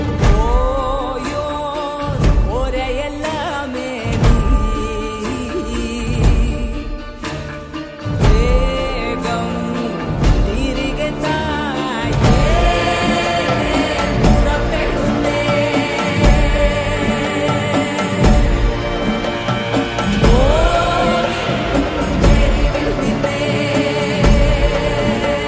Ringtone File
emotional Malayalam track